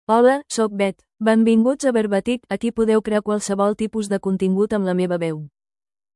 FemaleCatalan (Spain)
Beth — Female Catalan AI voice
Beth is a female AI voice for Catalan (Spain).
Voice sample
Listen to Beth's female Catalan voice.
Female
Beth delivers clear pronunciation with authentic Spain Catalan intonation, making your content sound professionally produced.